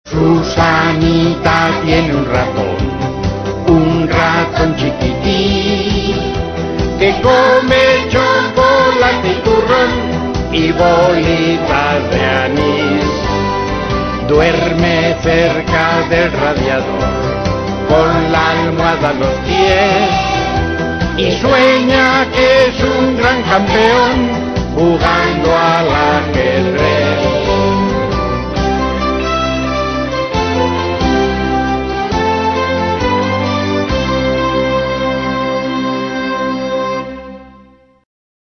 Canción infantil